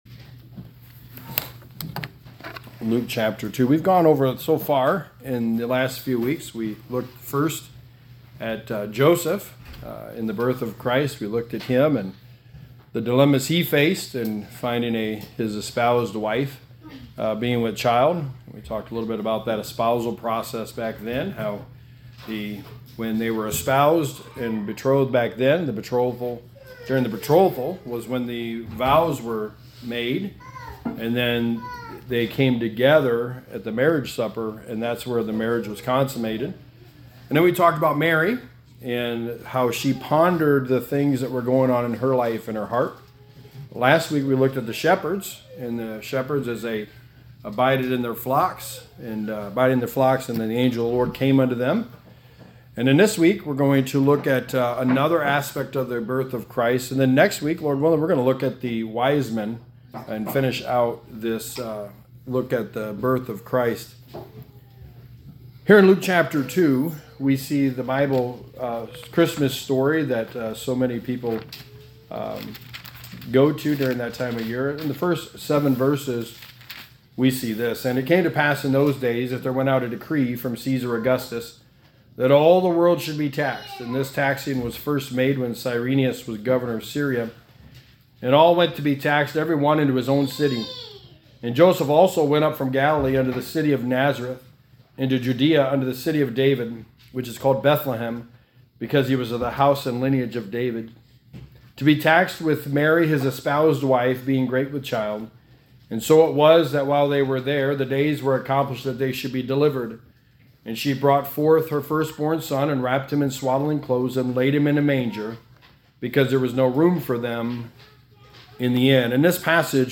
Sermon 4: The Birth of Christ – No Vacancy
Service Type: Sunday Morning